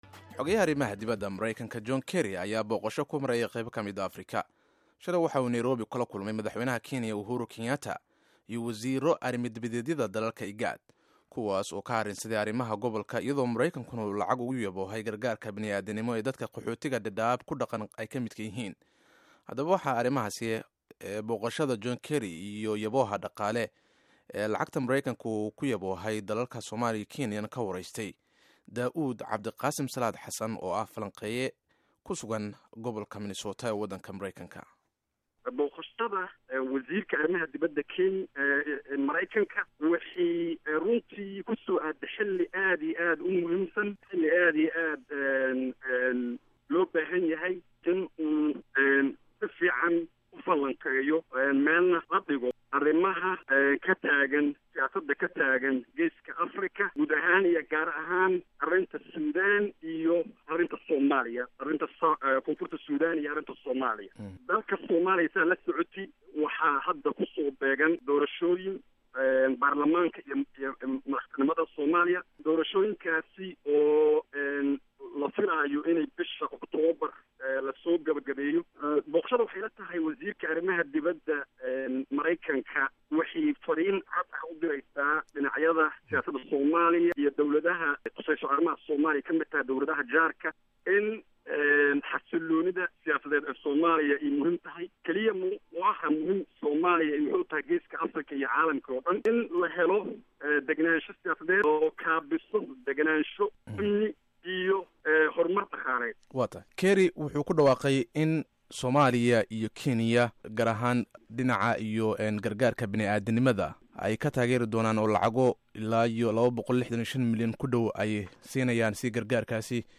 Dhageyso Wareysi Booqashada Kerry